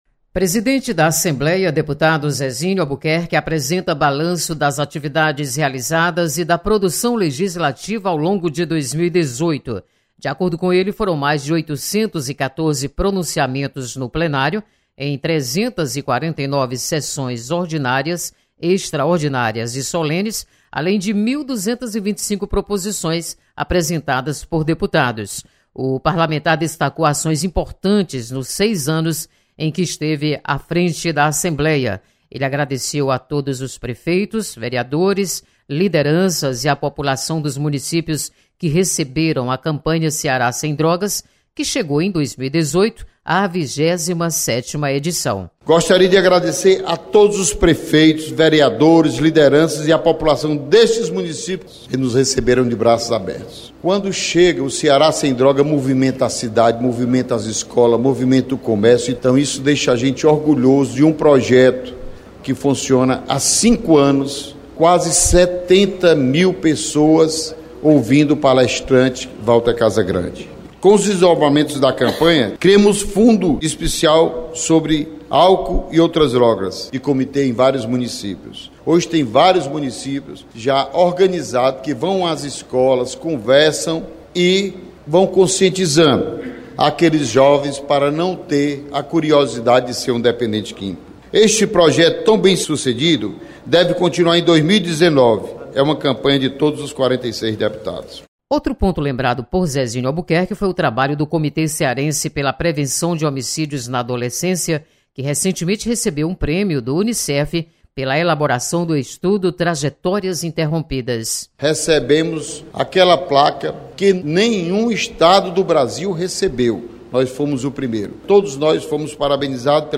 Presidente da Assembleia apresenta balanço das atividades realizadas em 2018.